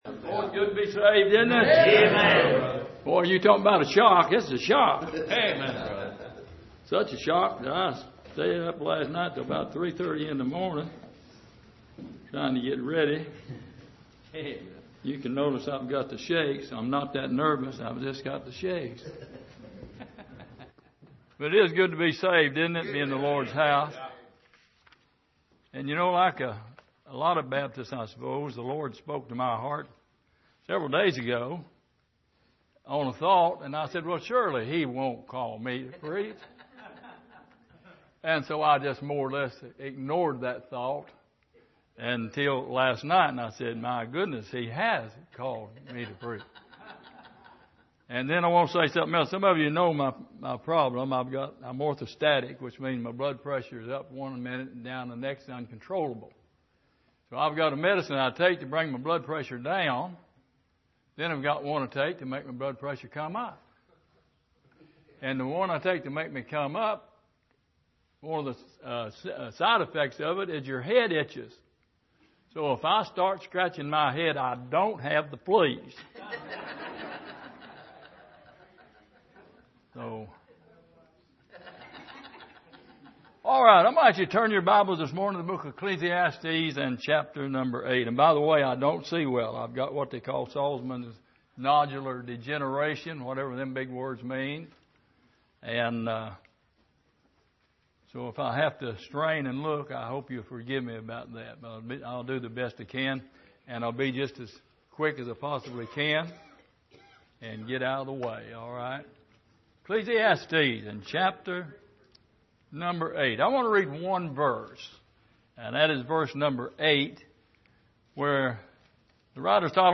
Passage: Ecclesiastes 8:8 Service: Missions Conference